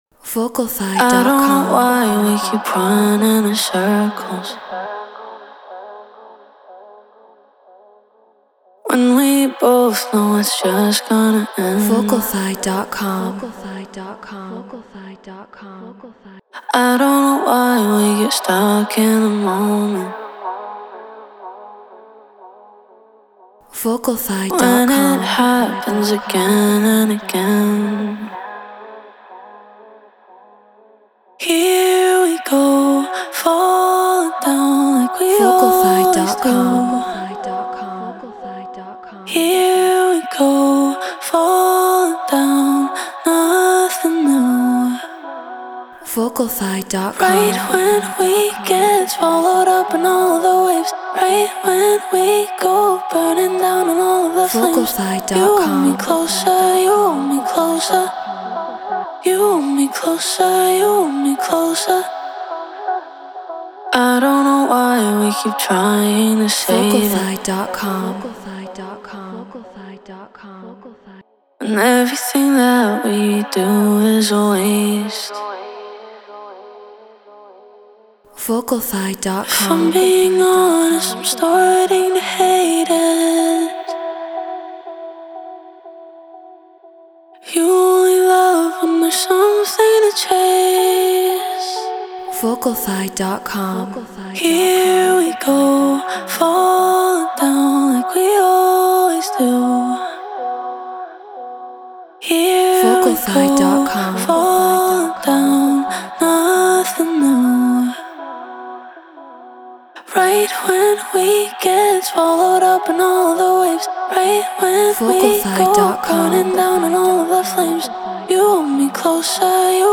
House 122 BPM C#min